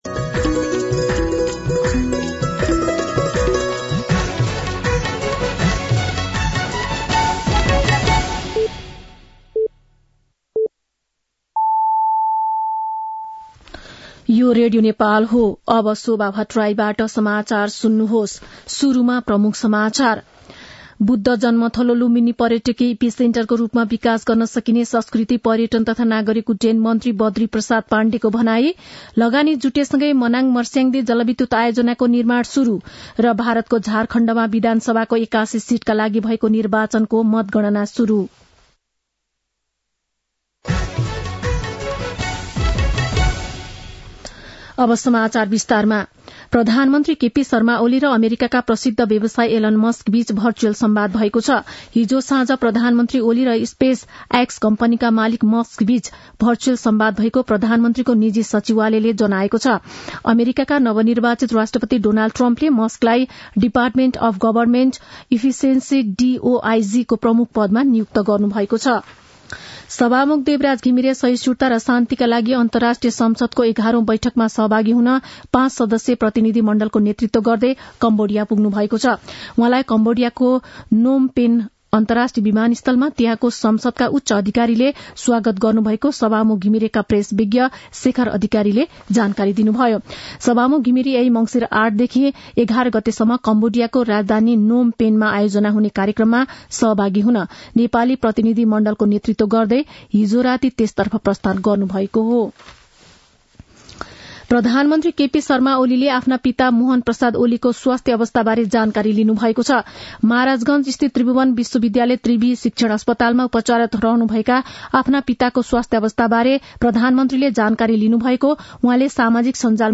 दिउँसो ३ बजेको नेपाली समाचार : ९ मंसिर , २०८१
3-pm-nepali-news.mp3